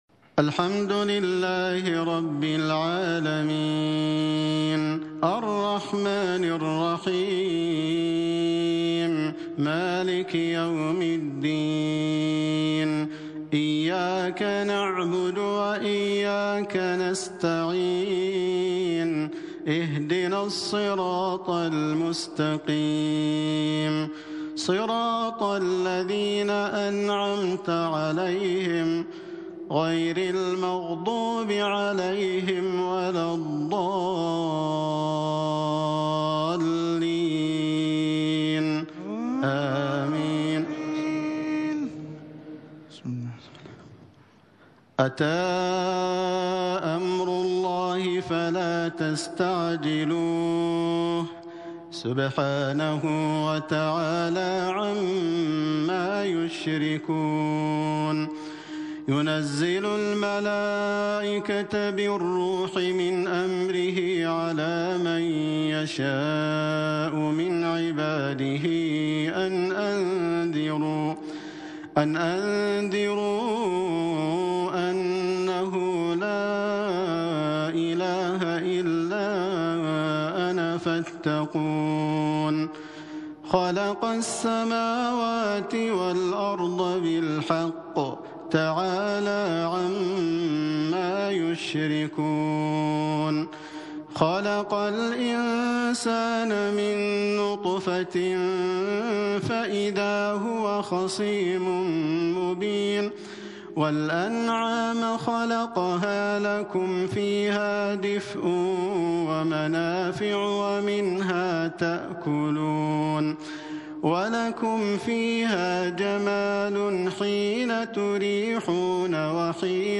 من جامع الشيخ زايد